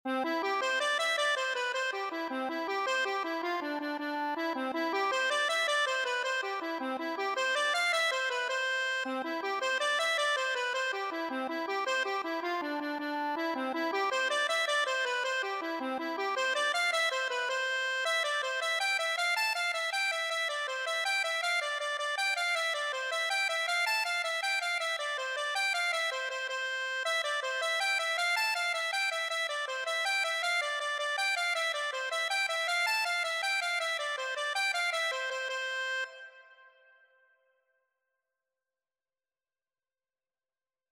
6/8 (View more 6/8 Music)
C major (Sounding Pitch) (View more C major Music for Accordion )
Accordion  (View more Intermediate Accordion Music)
Traditional (View more Traditional Accordion Music)
jacksons_fancy_ON905_ACC.mp3